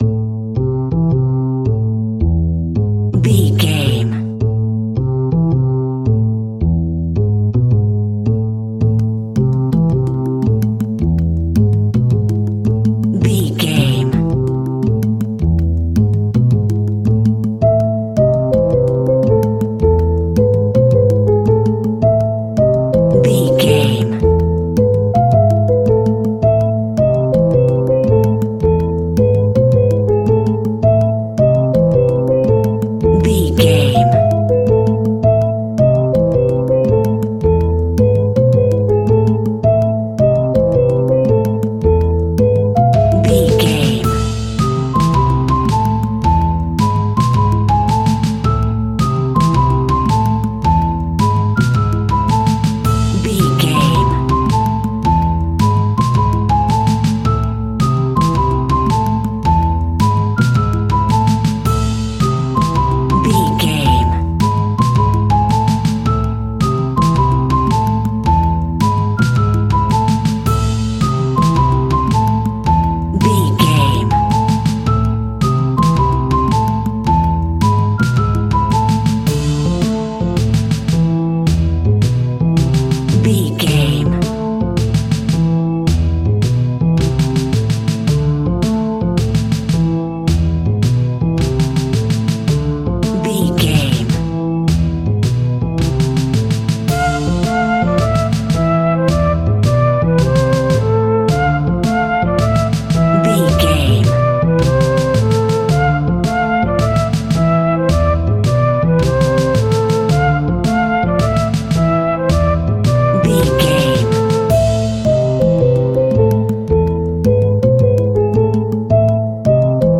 Aeolian/Minor
DOES THIS CLIP CONTAINS LYRICS OR HUMAN VOICE?
WHAT’S THE TEMPO OF THE CLIP?
ominous
haunting
eerie
playful
double bass
piano
drums
brass
spooky
horror music